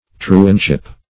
Search Result for " truantship" : The Collaborative International Dictionary of English v.0.48: Truantship \Tru"ant*ship\, n. The conduct of a truant; neglect of employment; idleness; truancy.